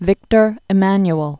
(vĭktər ĭ-măny-əl) 1820-1878.